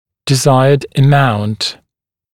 [dɪ’zaɪəd ə’maunt][ди’зайэд э’маунт]желательная величина